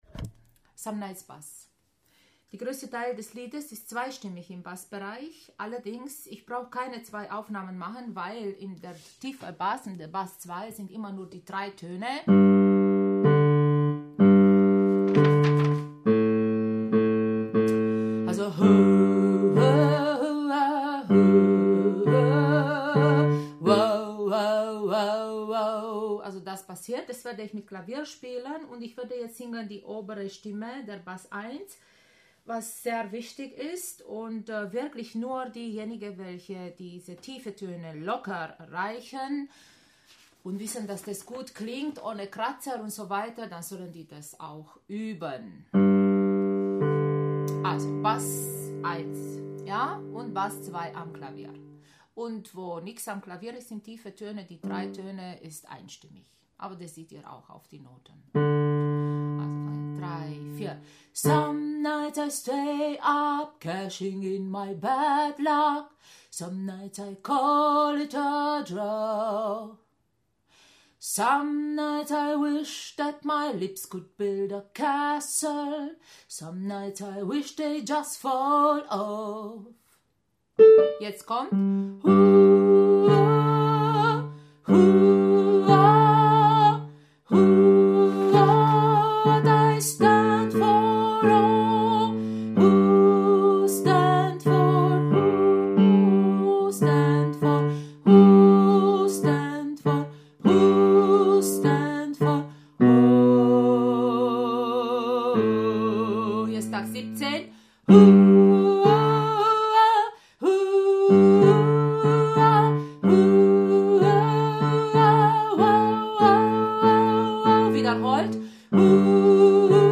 Some Nights – Bass